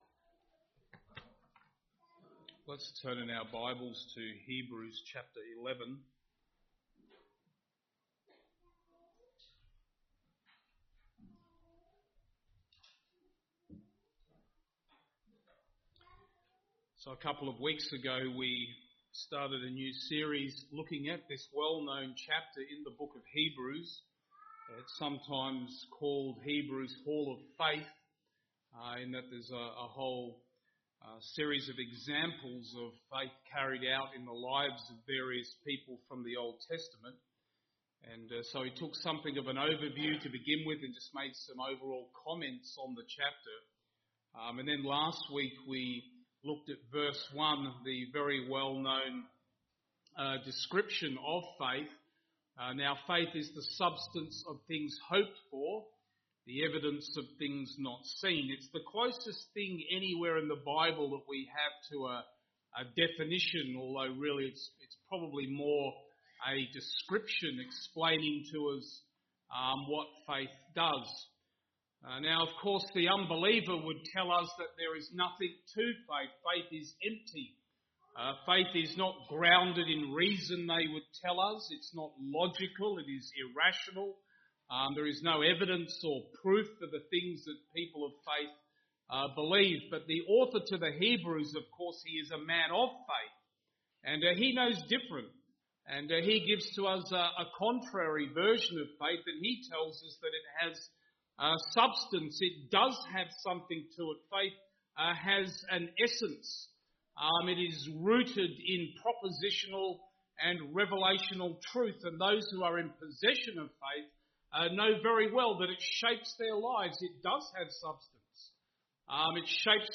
Posted in Sermons